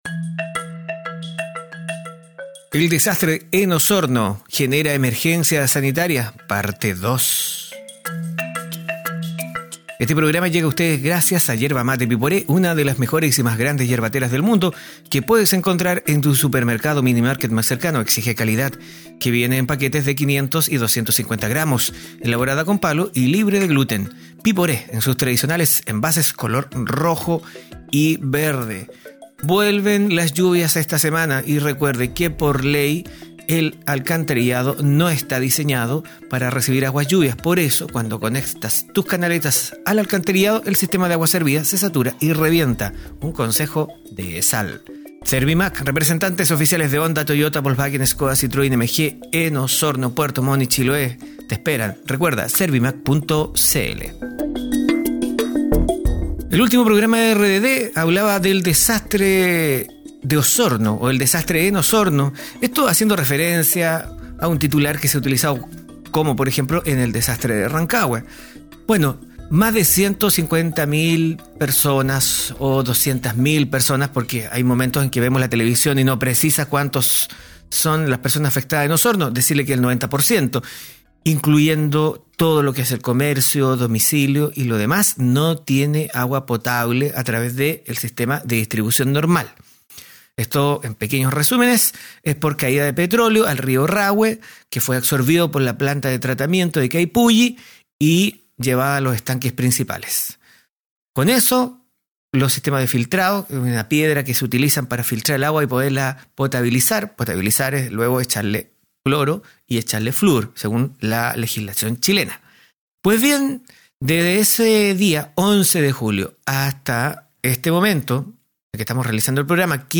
El jueves 11 de Julio la ciudad de Osorno se quedo sin agua potable, este 15 de julio, se realizaron nuevos puntos de prensa donde uno de los Gerentes de la sanitaria Essal y el Intendente de la Región "enfrentaron" a la Prensa.